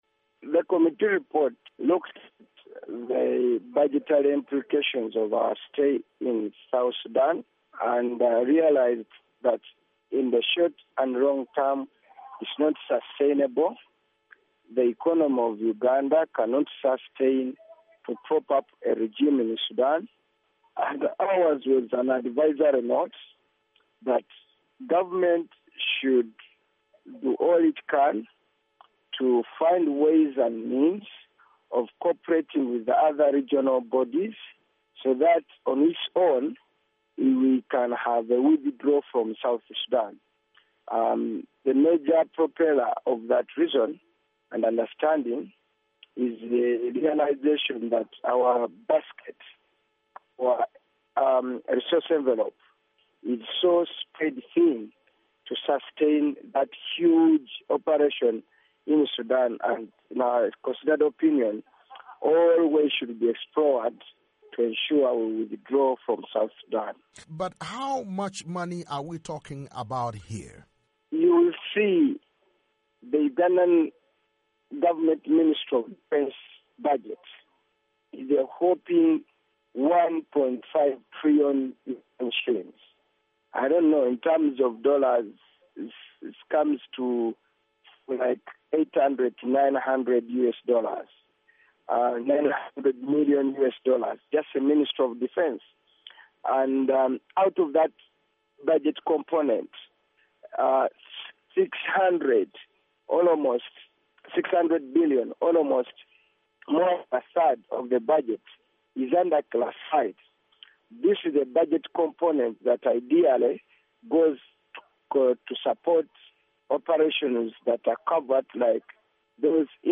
interview with Muwanga Kivumbi Muhammed, Uganda opposition parliamentarian